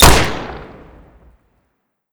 gunshot2.wav